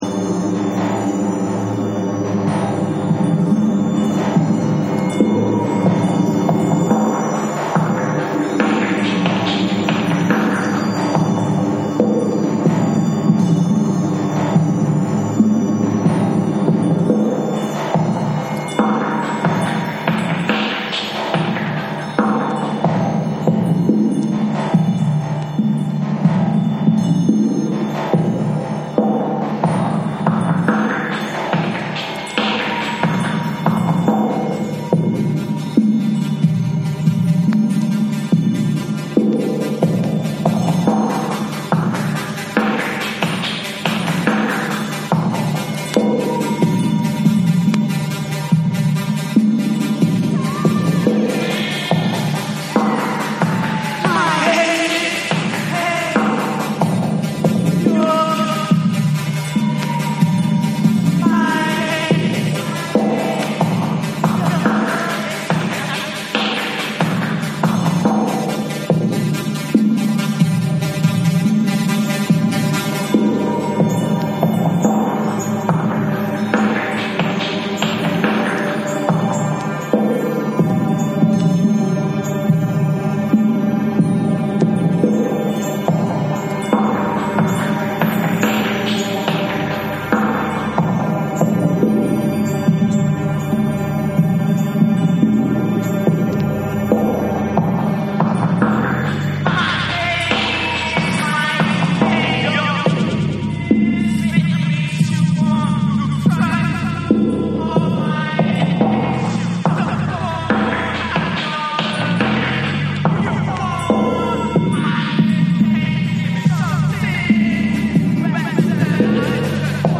ズブズブのサイケデリック・ダブにリミックスした1(SAMPLE 1)。
NEW WAVE & ROCK